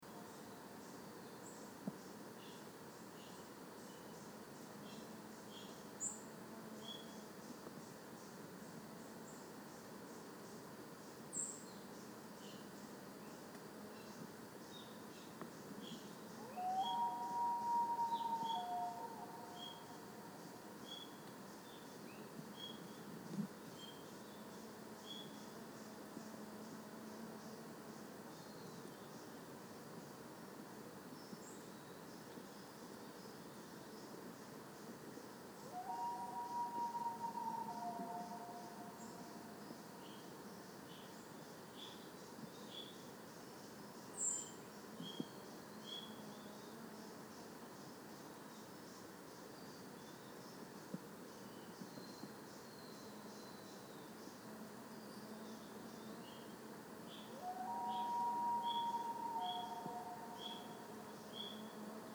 A common loon consistently calls off towards the northeastern tip of the lake.
A pileated woodpecker and common raven call nearby, even a great horned owl hoots from across the lake early on.
How many different critters vocalizing at Hatchet Lake can you identify
on this very early morning recording?